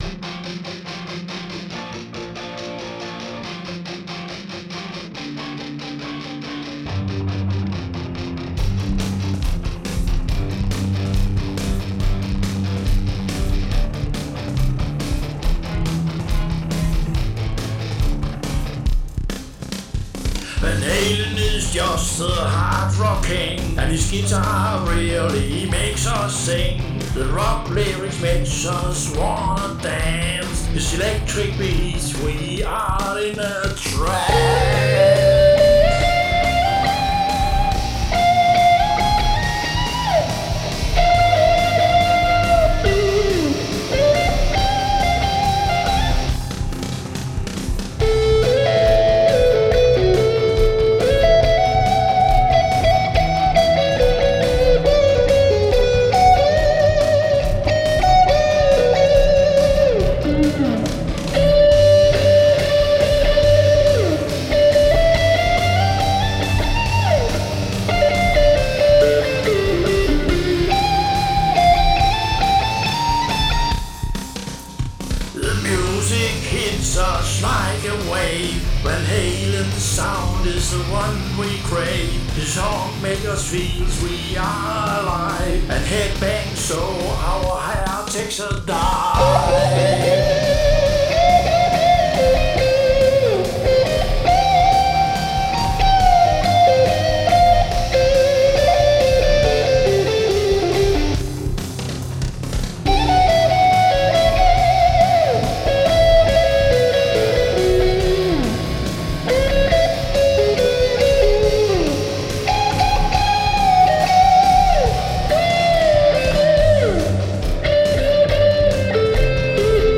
Electric Bass, Drums, Electric Guitars.
Genre: Heavy Metal Rock